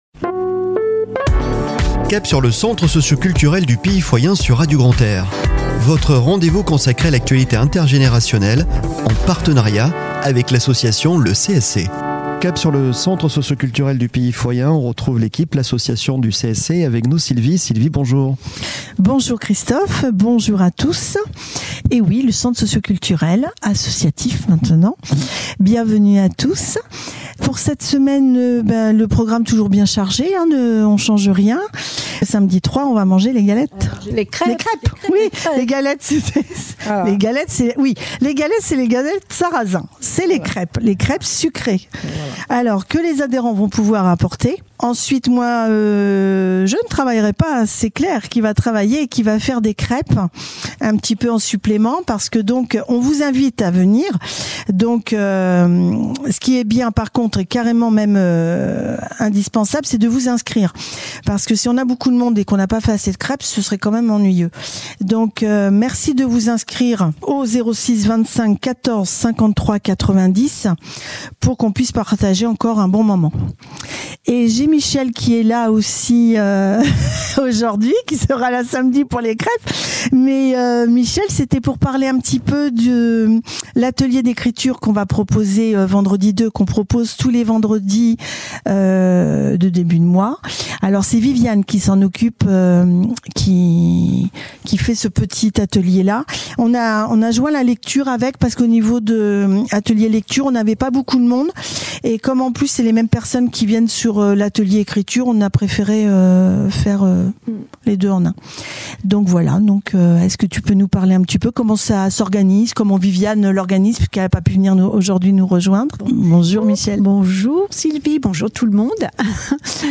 Chronique de la semaine du 29 Janvier au 04 Février 2024 !